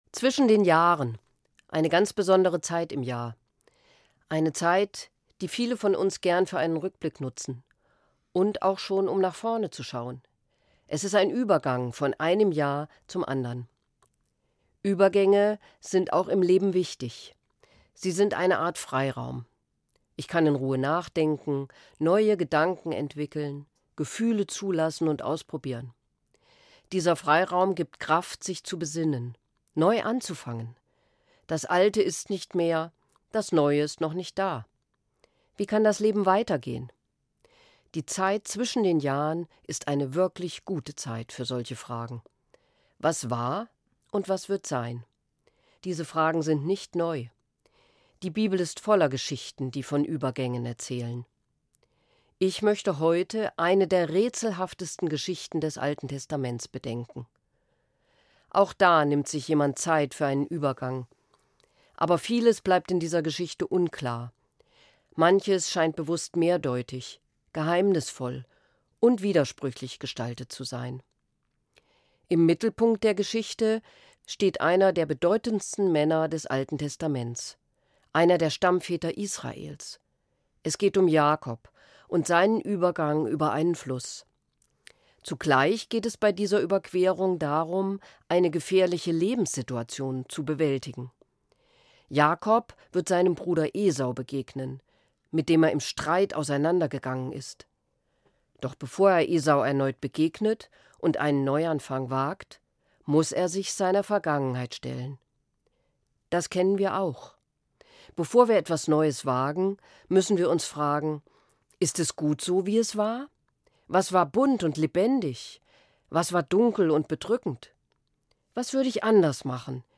Eine Sendung von Sabine Kropf-Brandau, Evangelische Pröpstin, Sprengel Hanau-Hersfeld